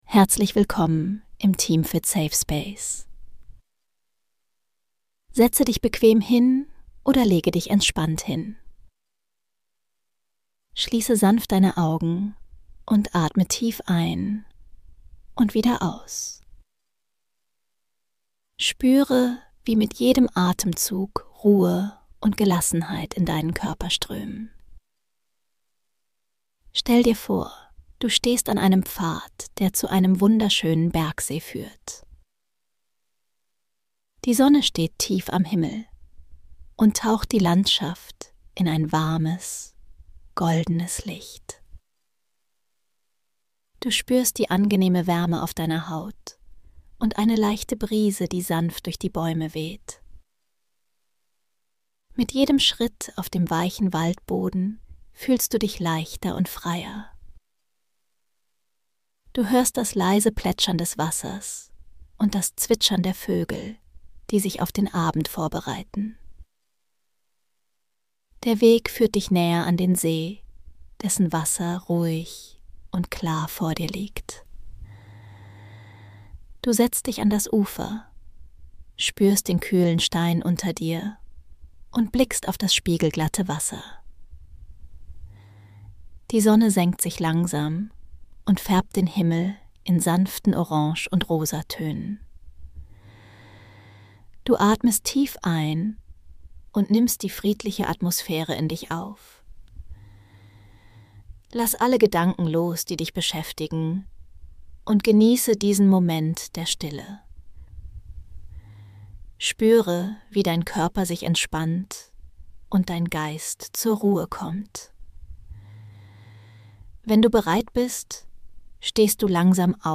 Entspanne bei einer geführten Traumreise zu einem ruhigen Bergsee